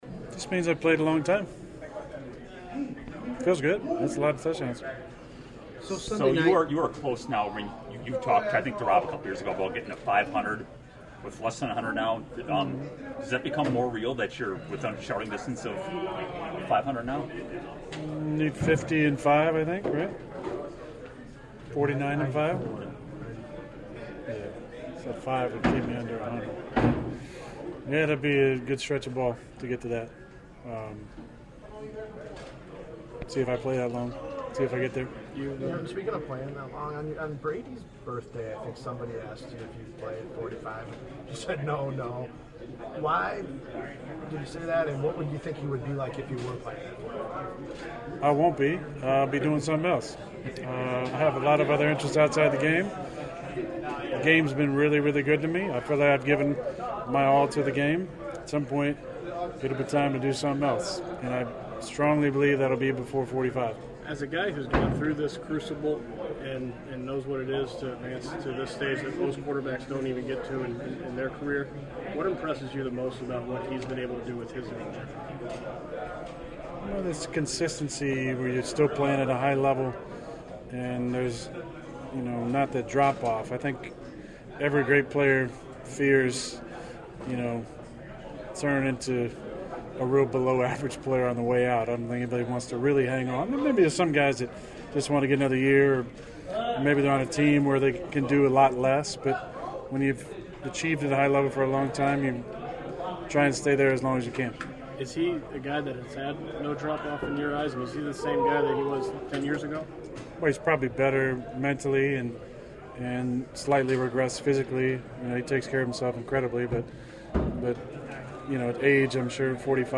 As is customary, the Packer quarterback held court with reporters today after practice.    He spent a good deal of time talking about the other number 12.